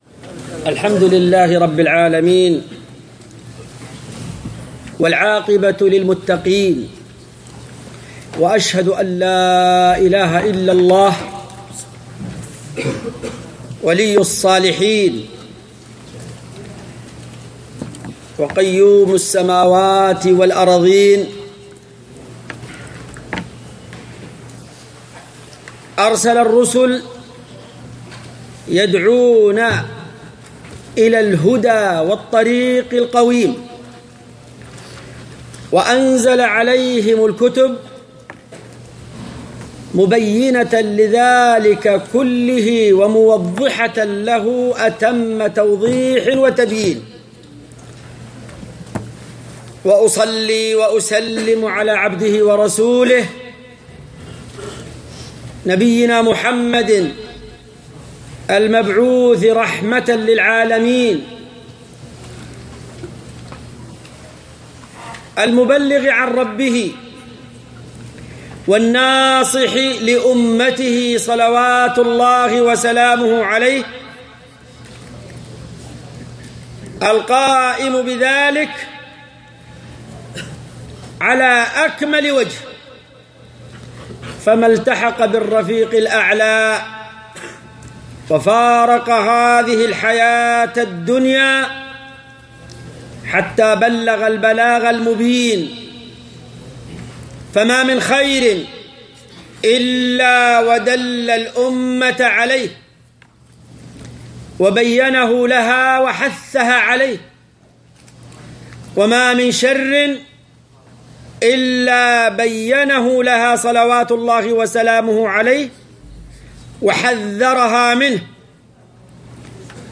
في مصلى كلية الحديث بالجامعة الإسلامية